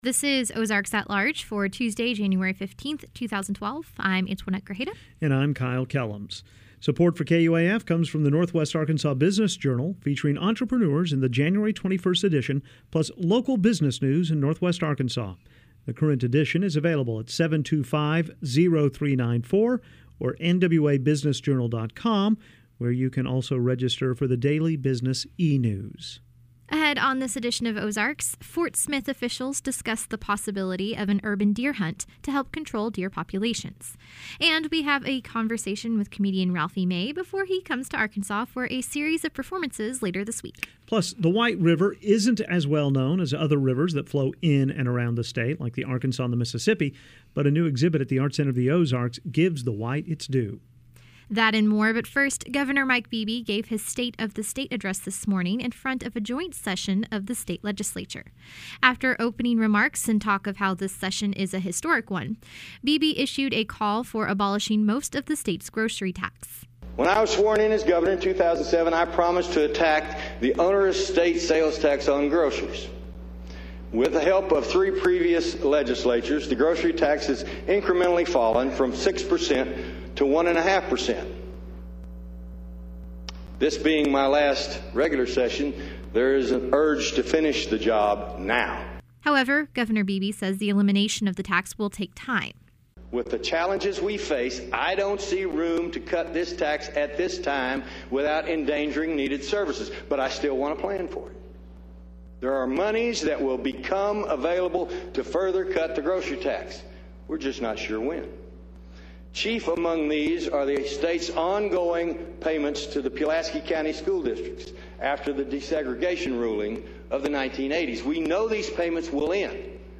Audio: oalweb011513.mp3 Ahead on this edition of Ozarks, Fort Smith officials discuss the possibility of an urban deer hunt to help control deer populations. And, we have a conversation with comedian Ralphie May before he heads to Arkansas for a series of performances later this week.